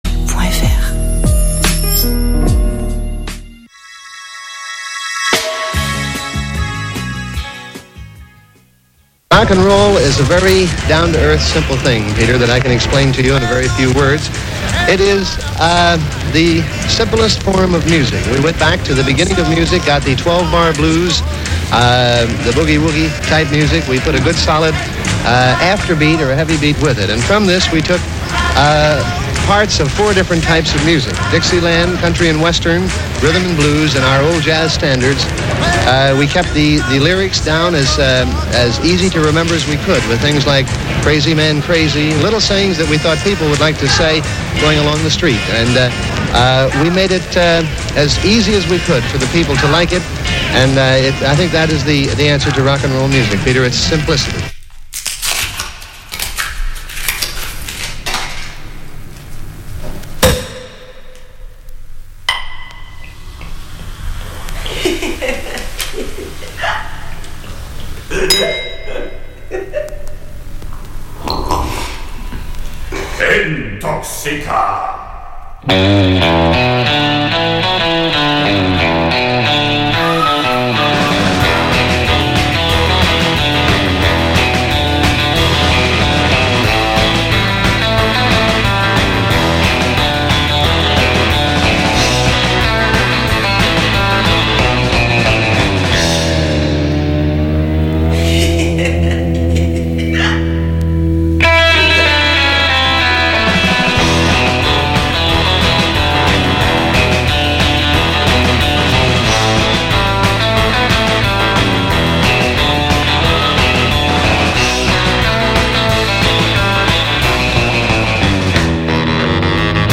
Vos esgourdes seront abreuvées de 50’s Rockabilly